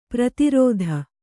♪ prati rōdha